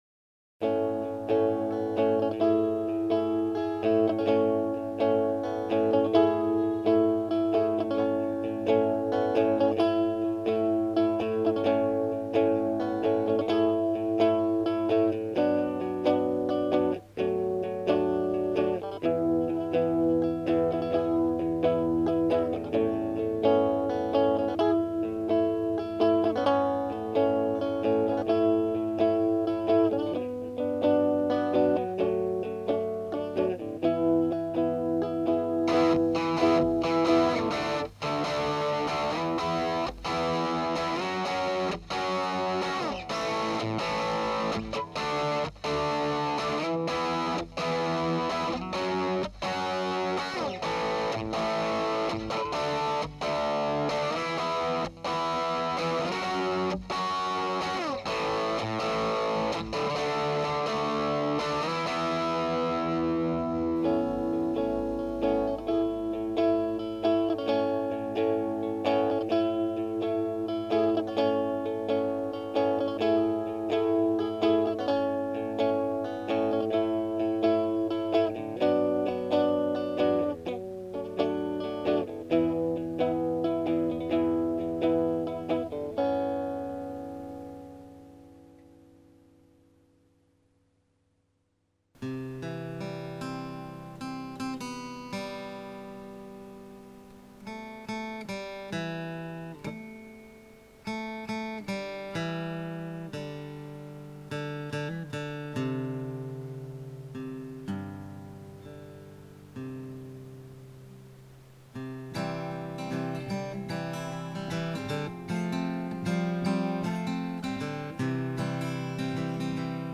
There is no transformer in the design. heres an mp3 of a few tiny home made amps. Noisy cricket and the deacy for the chorus, ovation, fender pro with son of clay jones(way too low volume,its late at night) only effect is a daw based reverb for the ovation. minimal processing so you get a feel of the mics ability.
Joe Meek mic JM37DP test.mp3